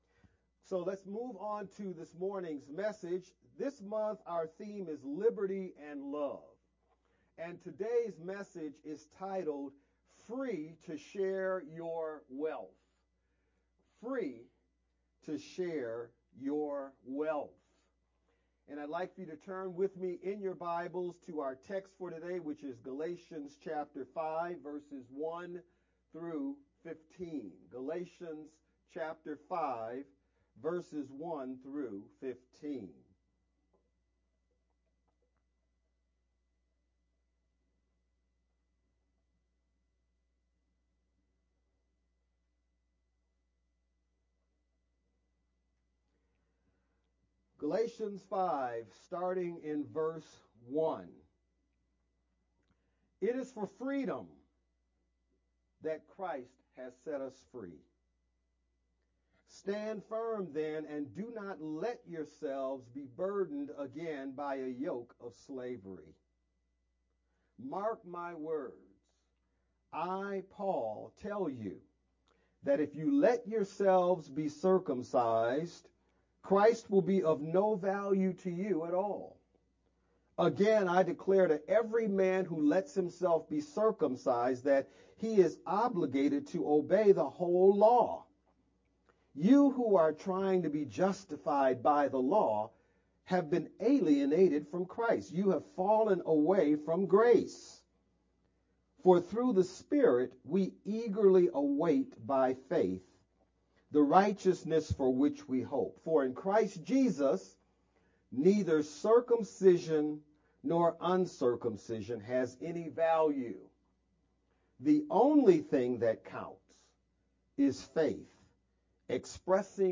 VBCC-Sermon-edited-1_14-sermon-only_Converted-CD.mp3